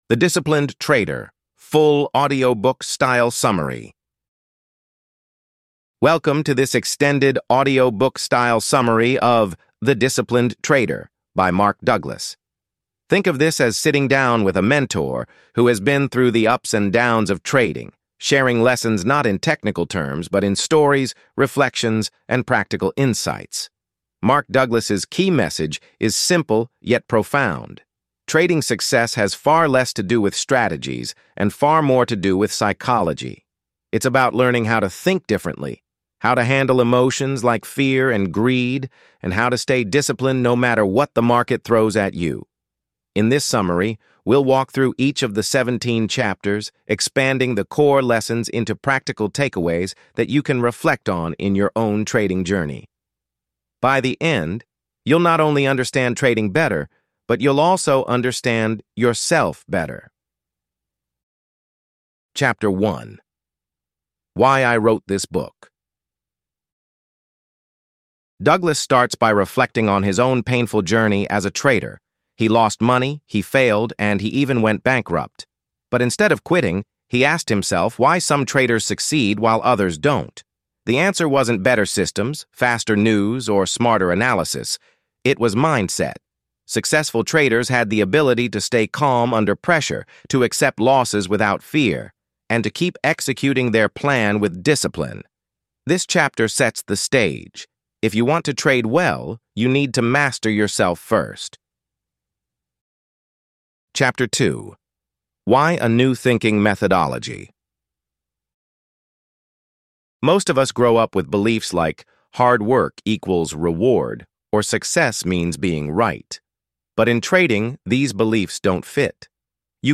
ElevenLabs_The_Disciplined_Trader_Full_Audiobook_Summary.pdf.mp3